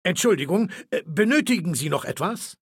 Datei:Maleold01 ms06 hello 000681b9.ogg